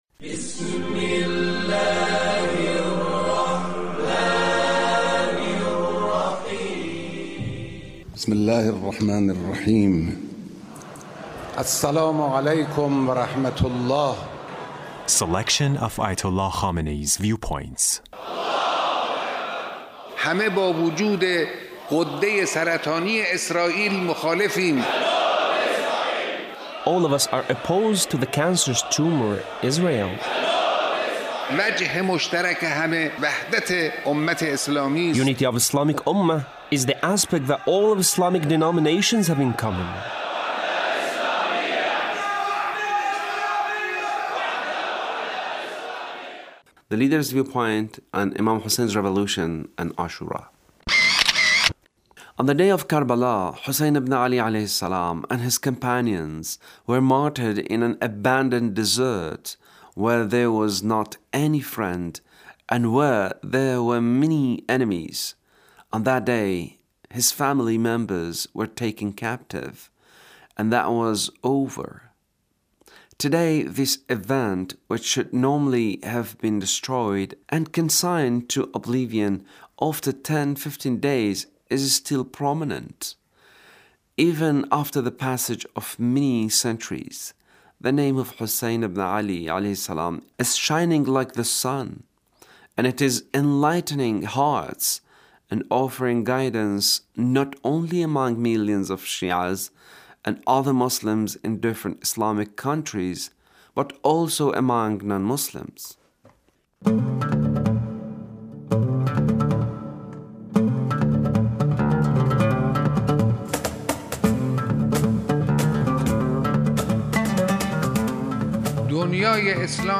Leader's speech (1474)